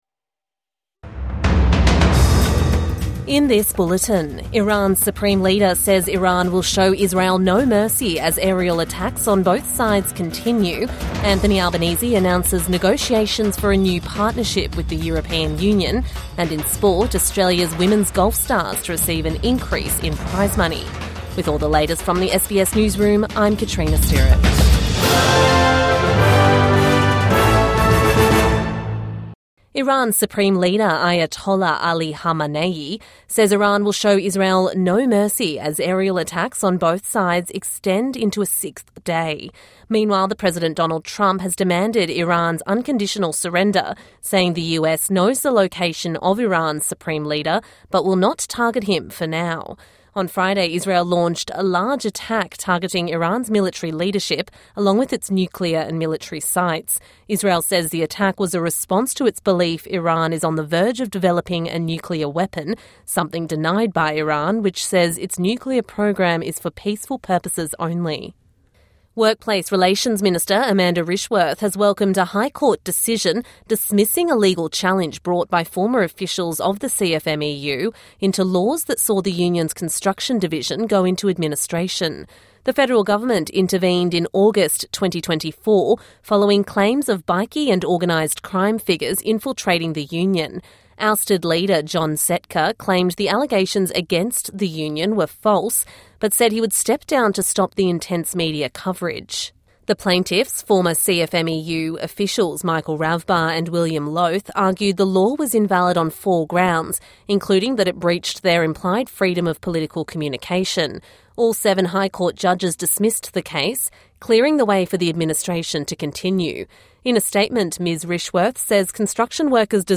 Show Israel no mercy, says Iran’s Supreme Leader | Evening News Bulletin 18 June 2025